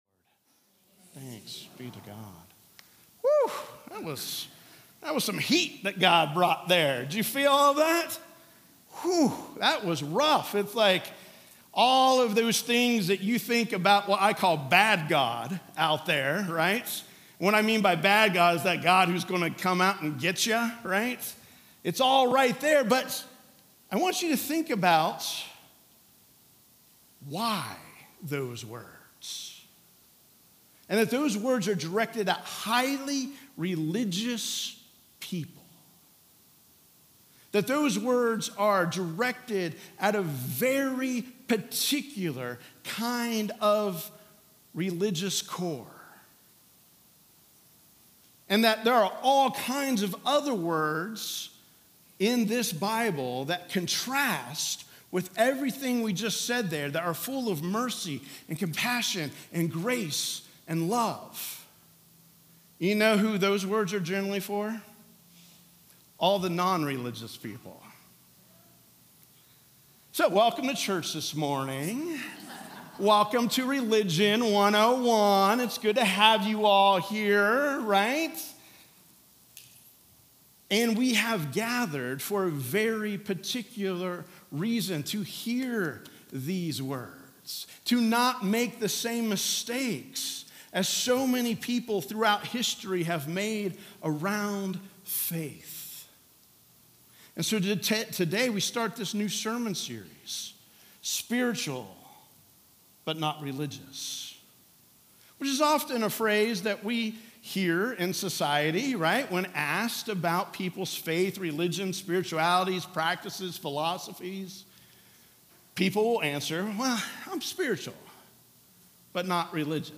Sermons | Grace Presbyterian Church
Join the community at Grace on this beautiful Sunday morning as we worship and feel empowered by a wonderful sermon.